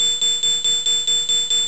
ping_fast.wav